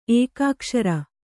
♪ ēkākṣara